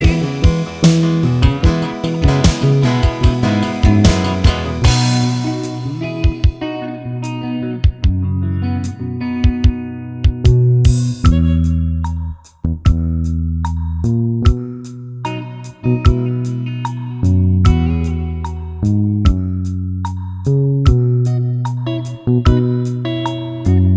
Two Semitones Down Soul / Motown 3:16 Buy £1.50